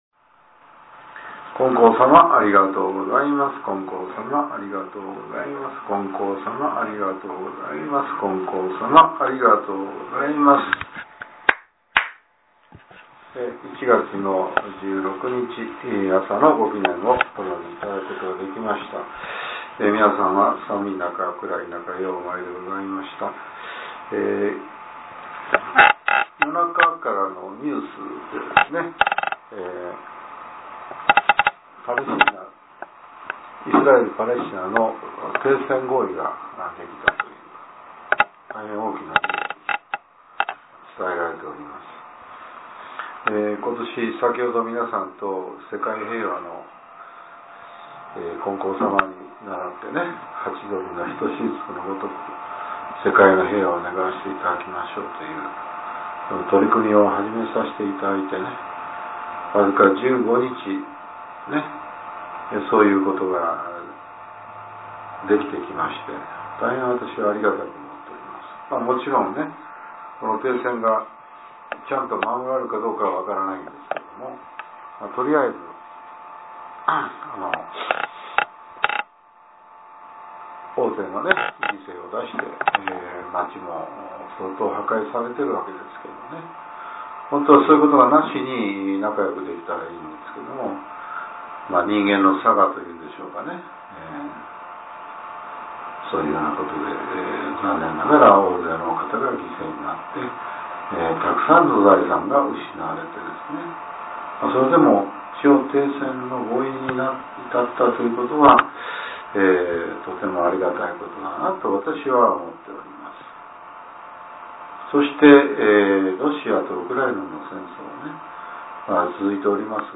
令和７年１月１６日（朝）のお話が、音声ブログとして更新されています。